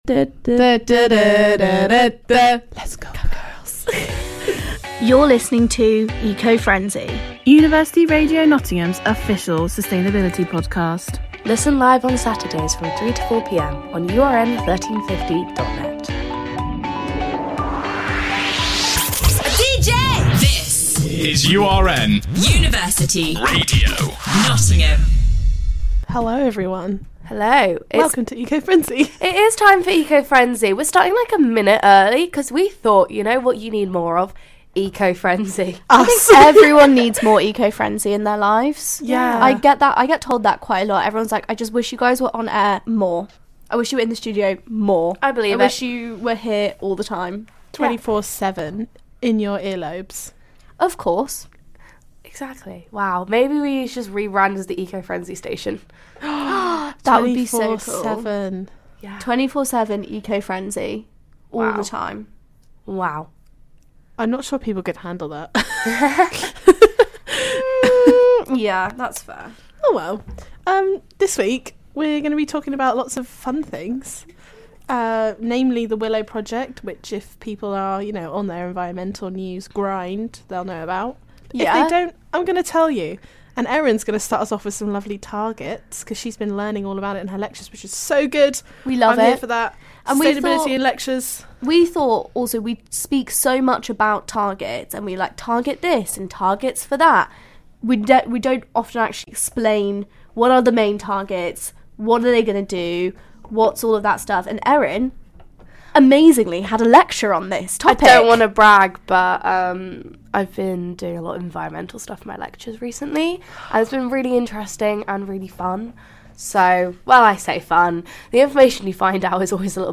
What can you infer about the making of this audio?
~ Originally broadcast live on University Radio Nottingham on 4th March 2023.